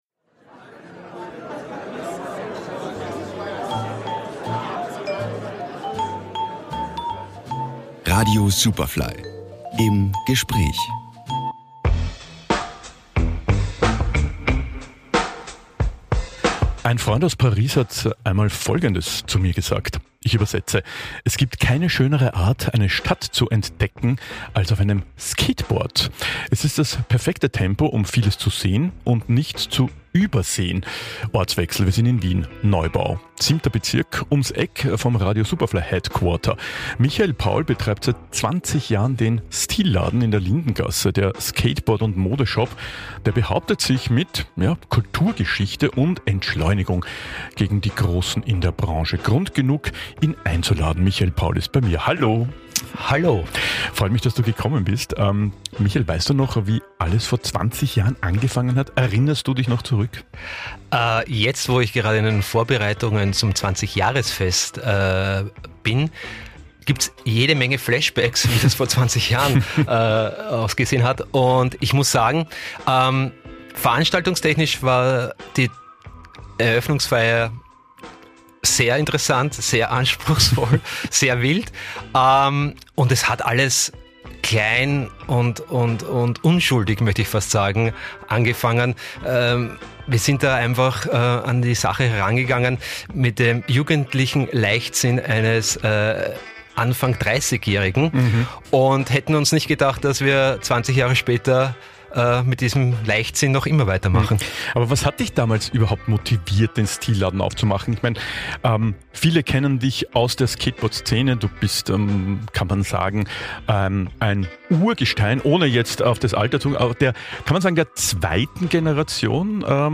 Superfly Interviews | 20 Jahre Stil Laden